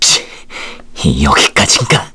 Roi-Vox_Dead_kr.wav